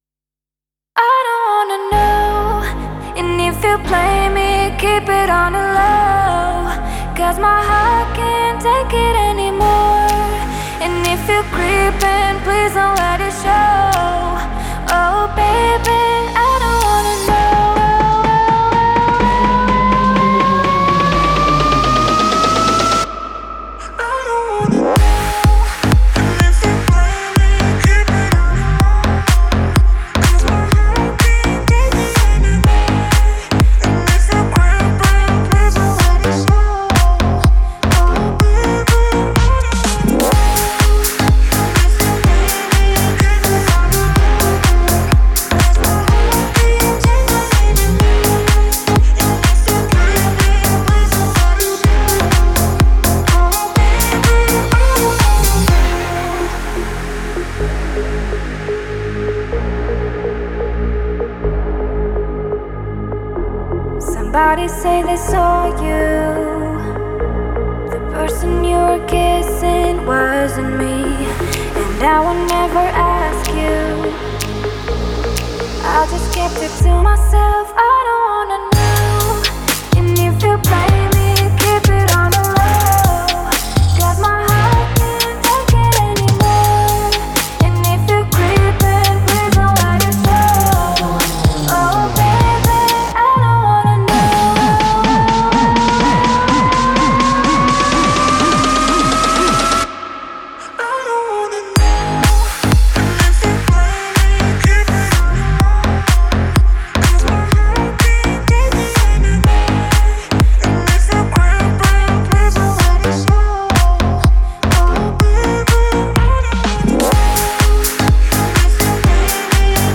это энергичная и зажигательная песня в жанре EDM